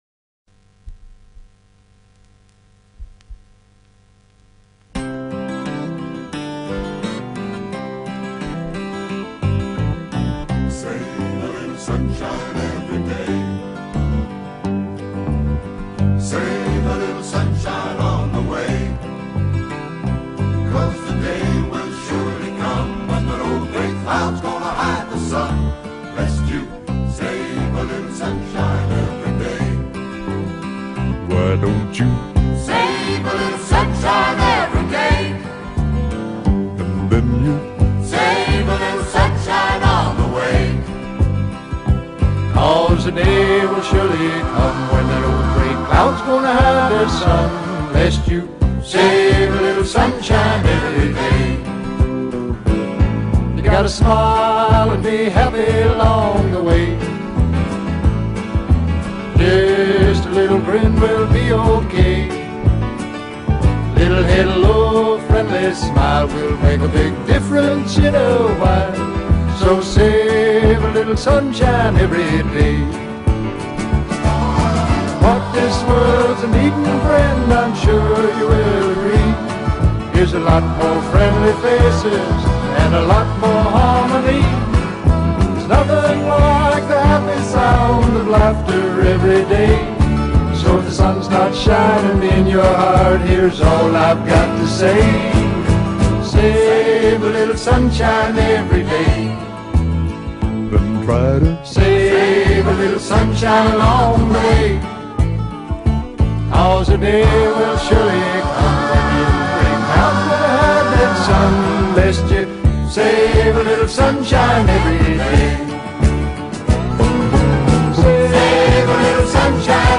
композитором и весьма неплохим исполнителем кантри-песен.